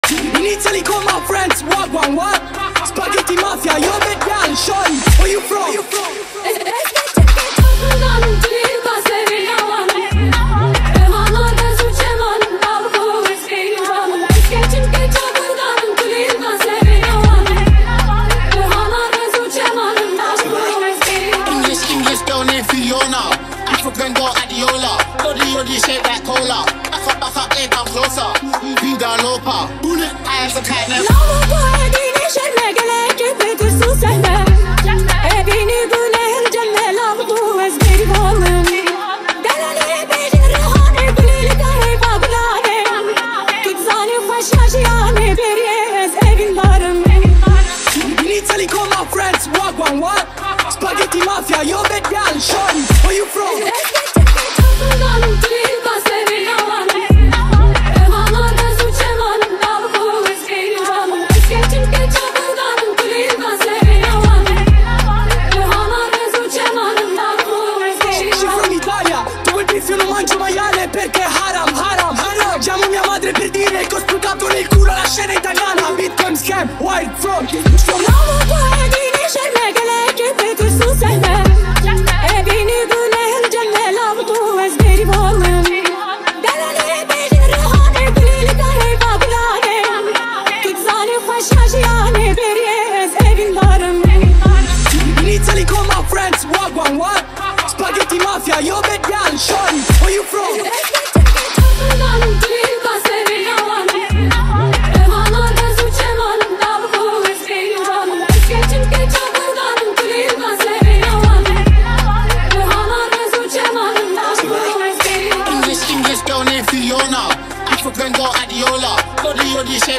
Kurdish Music
soulful voice and heartfelt performances
With powerful lyrics and emotional melodies